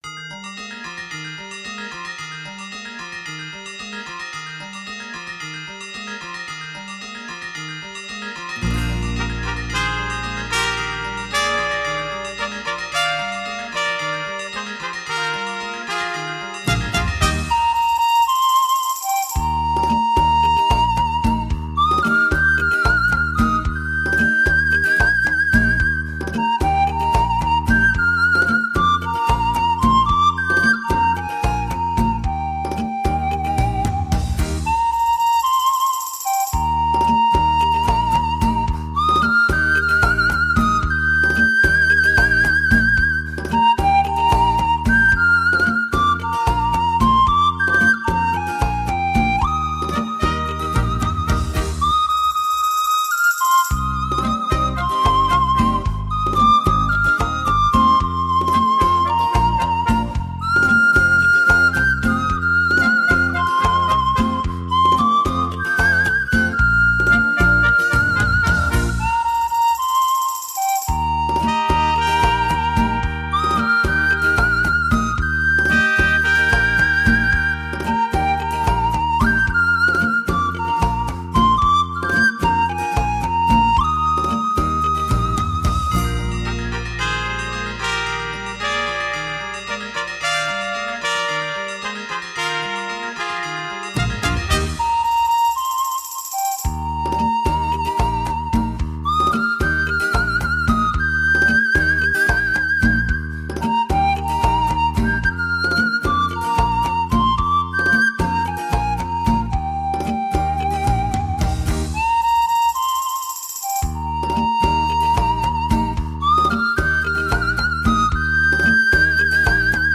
用古老的中国乐器奏异国他乡的音乐。
印度尼西亚民歌
排箫